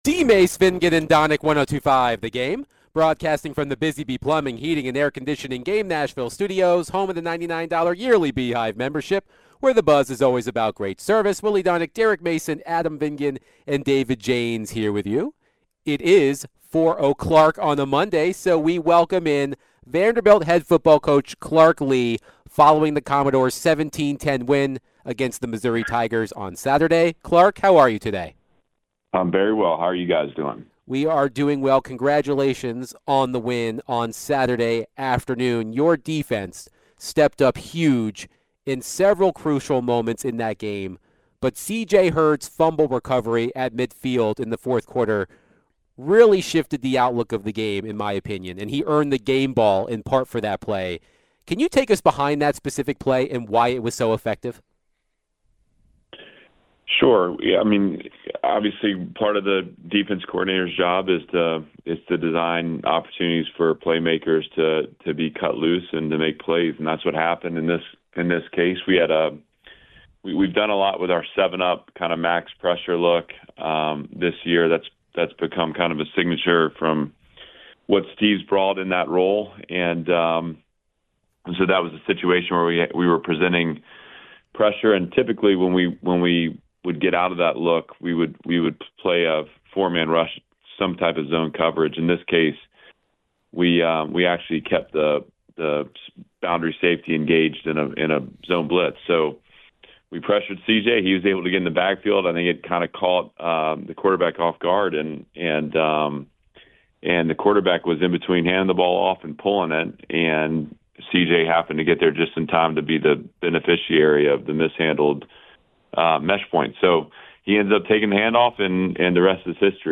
Vanderbilt Football Head Coach Clark Lea joined DVD to recap the Vandy 17-10 win over Mizzou, College Gameday, Diego Pavia, and more